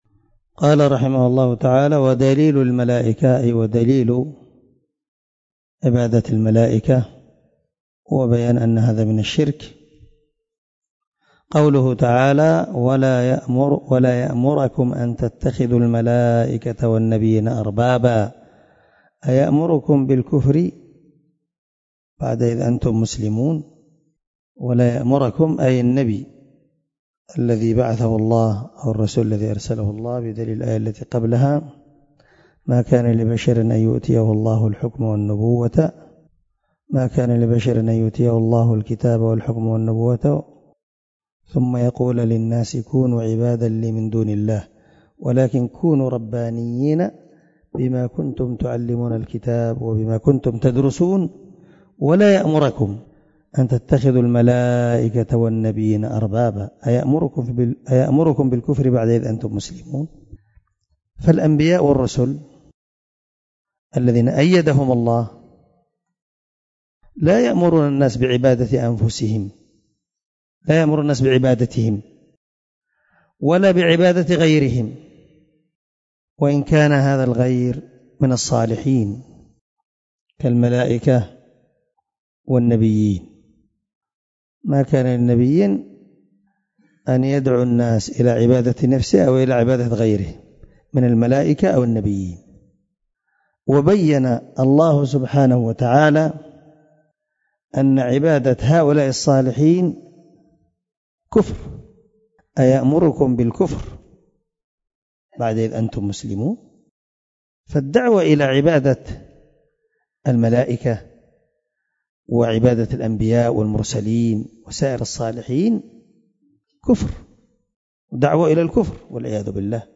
الدرس 9 تابع القاعدة الثالثة دليل الملائكة من شرح القواعد الأربع
دار الحديث- المَحاوِلة- الصبيحة.